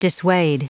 Prononciation du mot dissuade en anglais (fichier audio)
Prononciation du mot : dissuade